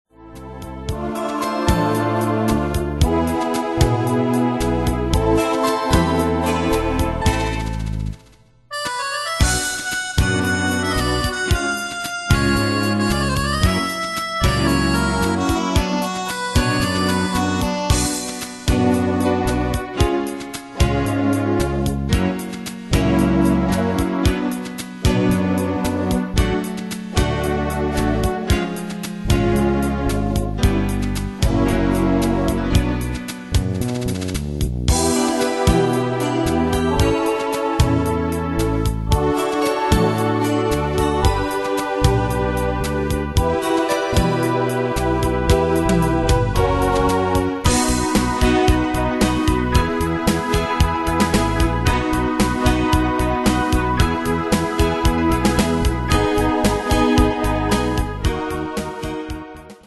Danse/Dance: Pop Cat Id.
Pro Backing Tracks